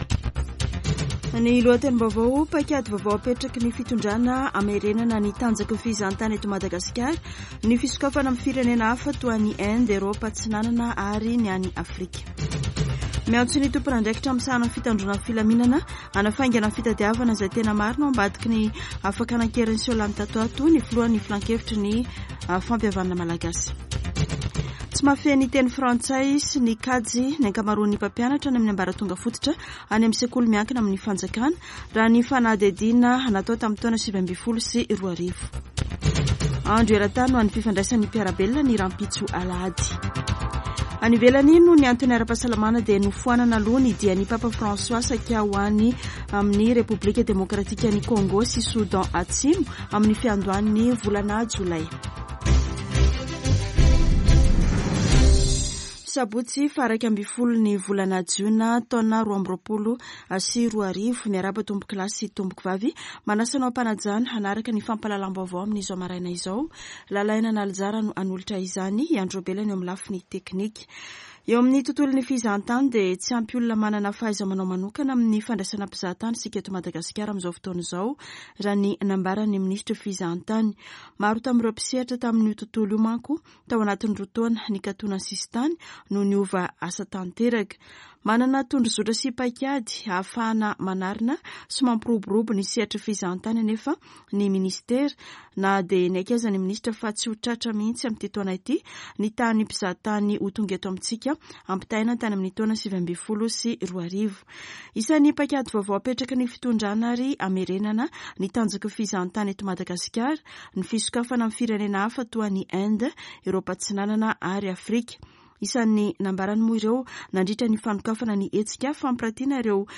[Vaovao maraina] Sabotsy 11 jona 2022